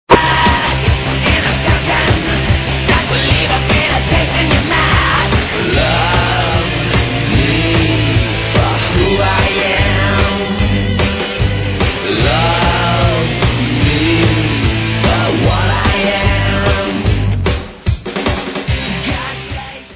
Metal in the vein of: marilyn manson meets korn